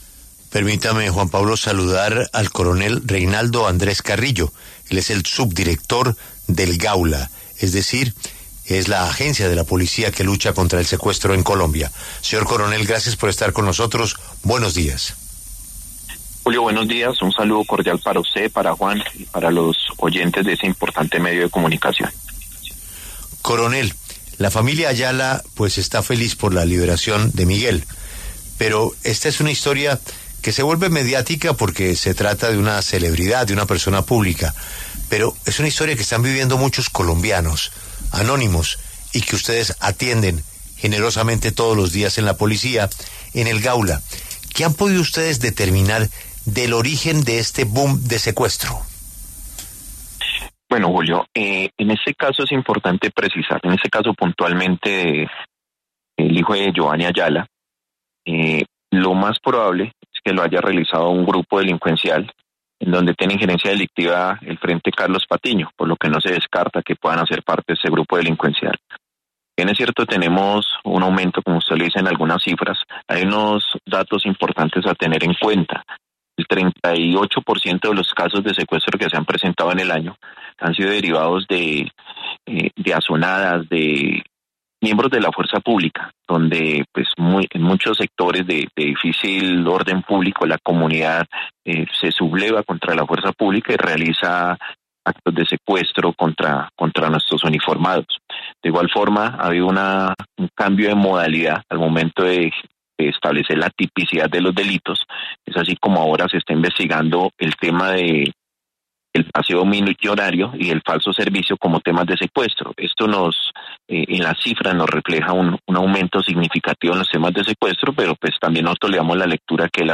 En entrevista con La W, el coronel Reinaldo Andrés Carrillo, subdirector del Gaula, explicó cómo operó la estructura responsable y confirmó el uso de la modalidad conocida como outsourcing criminal, cada vez más frecuente entre grupos armados.